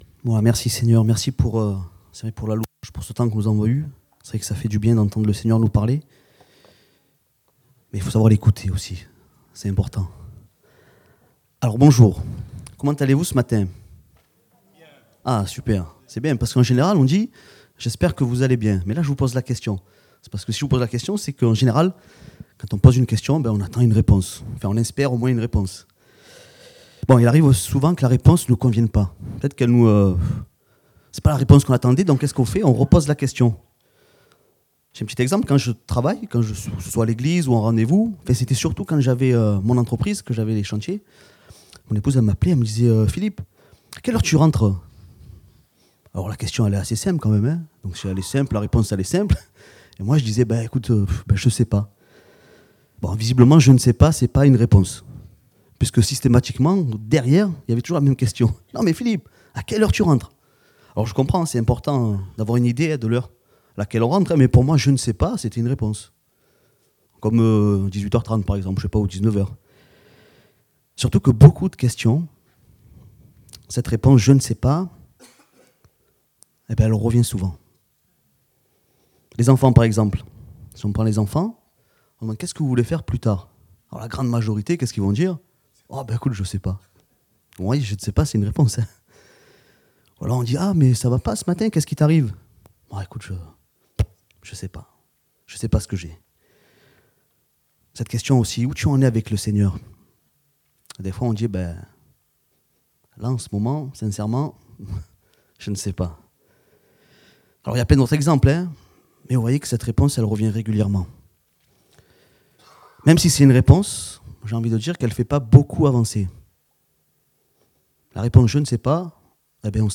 Date : 26 mai 2024 (Culte Dominical)